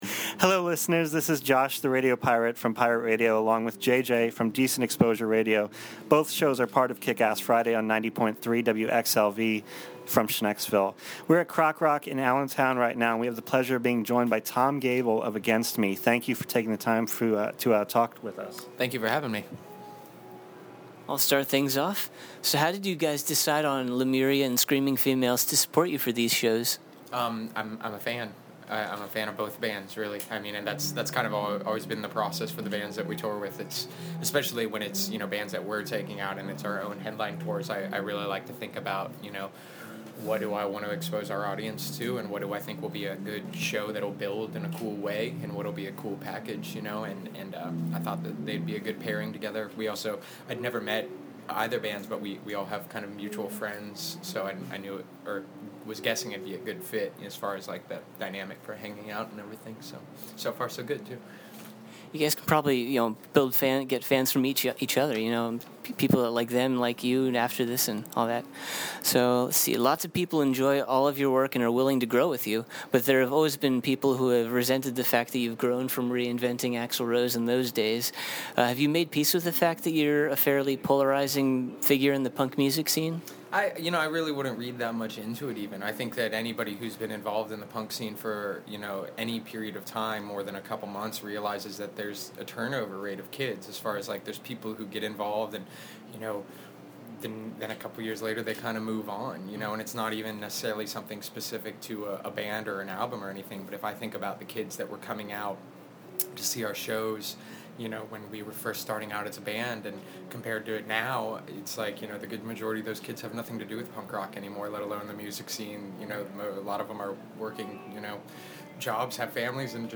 Interview – Tom Gabel of Against Me!
As promised, here is the interview we did with Tom Gabel when Against Me! came to Allentown!
against-me-interview.mp3